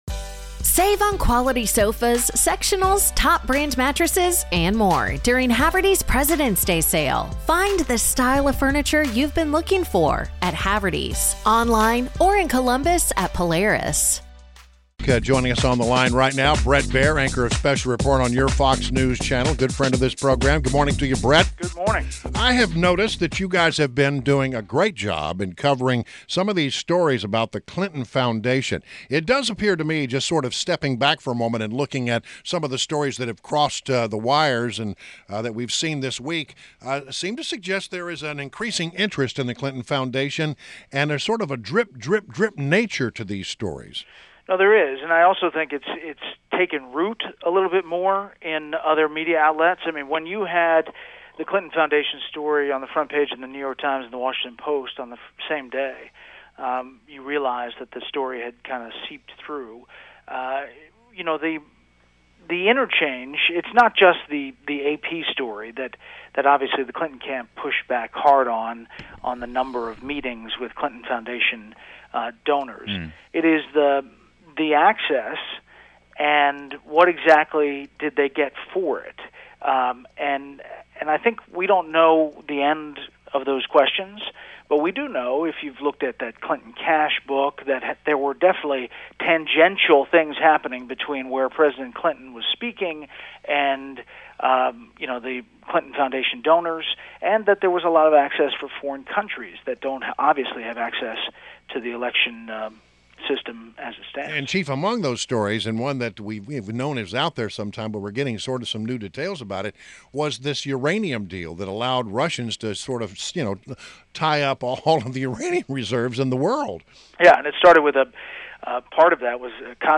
WMAL Interview - BRET BAIER - 08.26.16